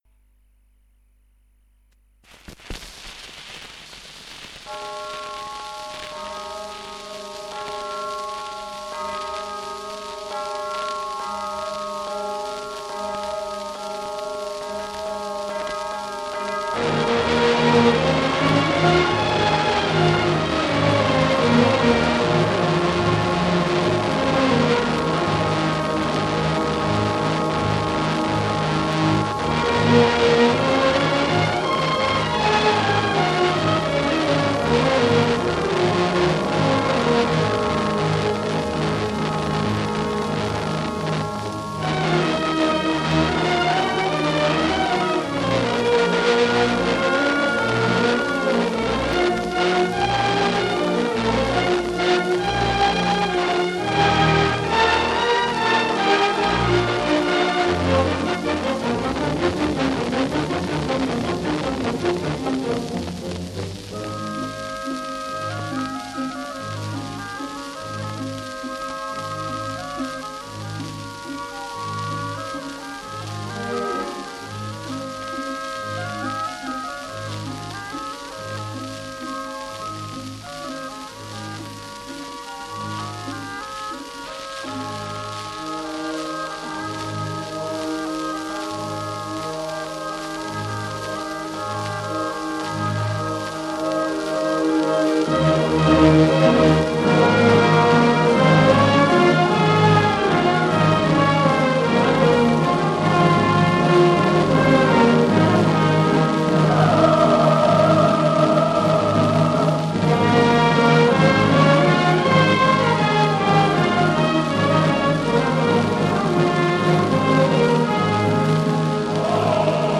undici dischi a 78 giri del 1943 in cui è registrata la Cavalleria Rusticana
La registrazione è avvenuta a Milano sotto l’egida della celeberrima casa discografica LA VOCE DEL PADRONE.
Orchestra e Coro del Teatro alla Scala.
N 4.- Gli aranci olezzano... Scena 1.a Coristi e Orchestra Scena 1.a        SCARICA